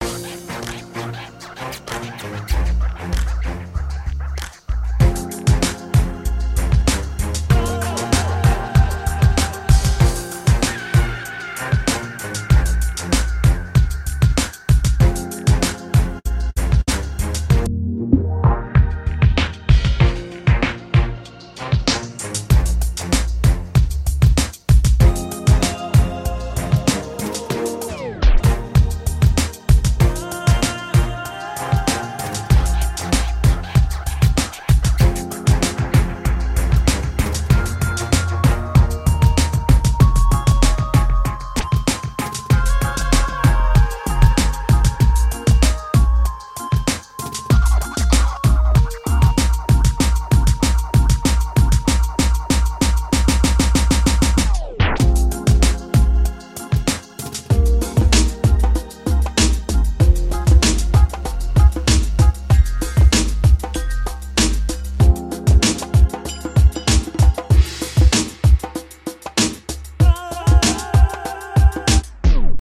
BPM192
Audio QualityPerfect (High Quality)
Time for a little trip to the roots of hip-hop.